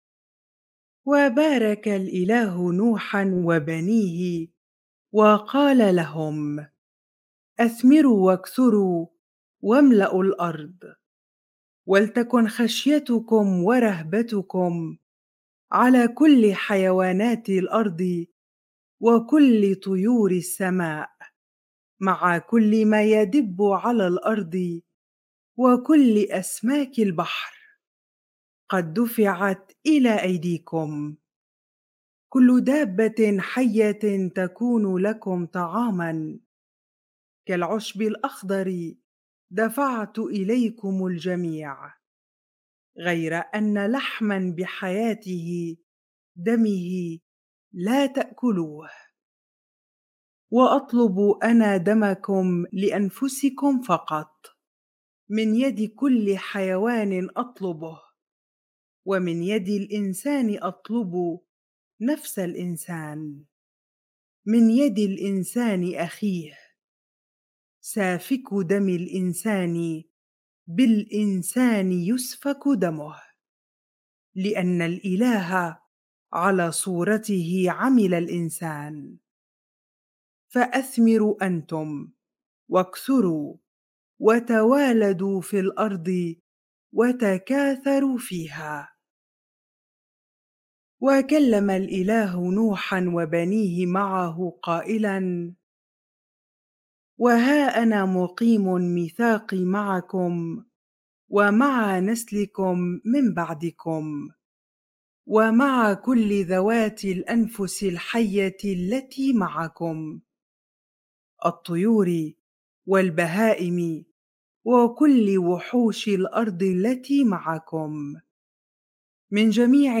bible-reading-genesis 9 ar